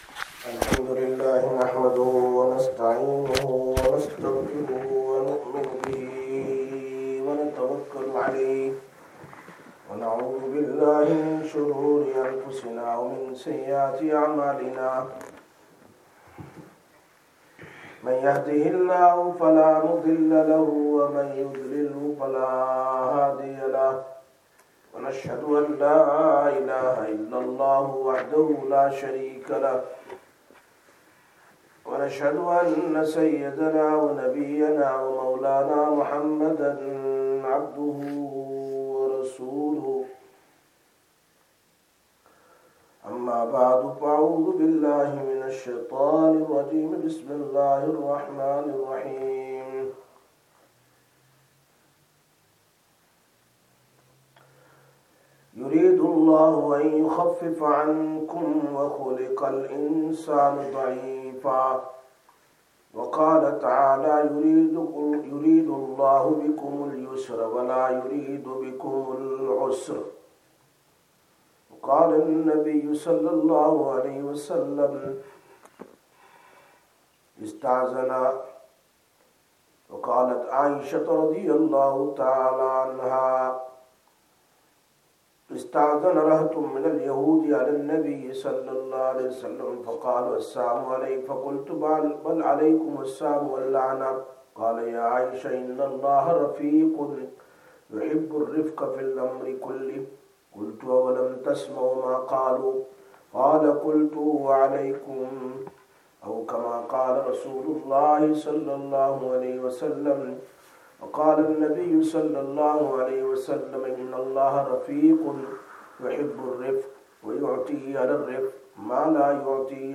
16/10/2024 Sisters Bayan, Masjid Quba